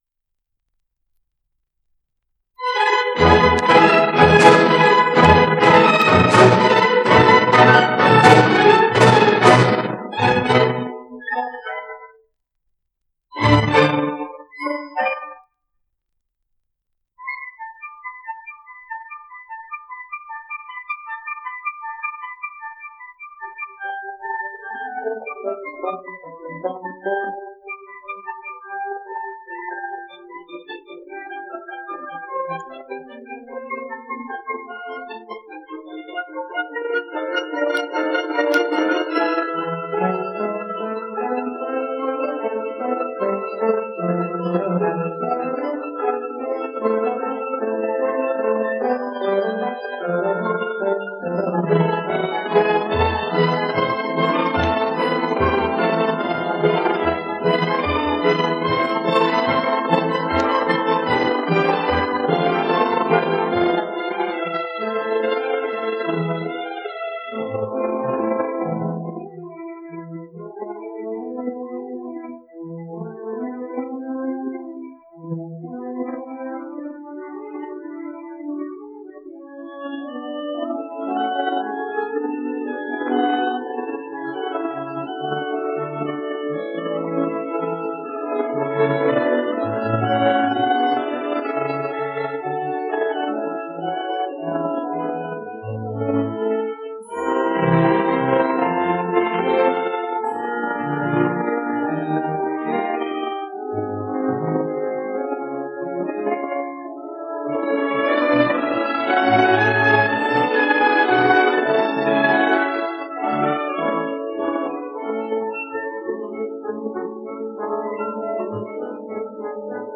La Verbena de la Paloma : Preludio (sonido remasterizado)